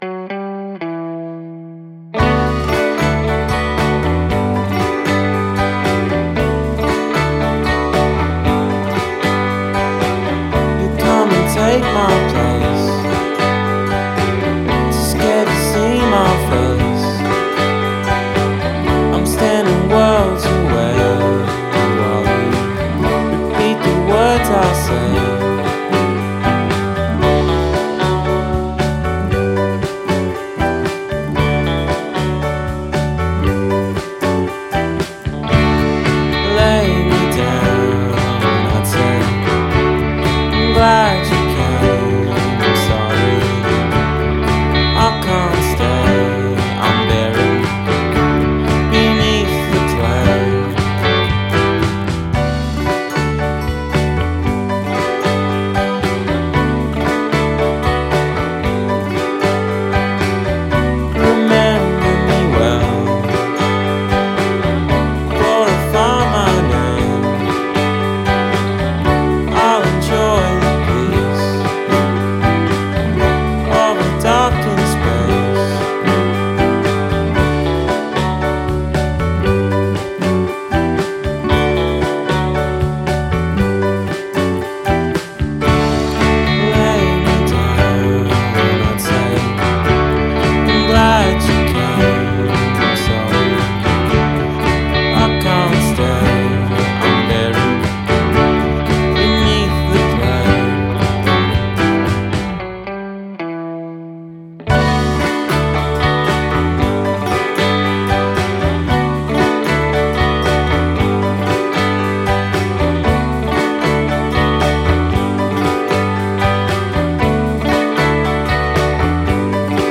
ormai capitale del jangle-pop dell'altro emisfero.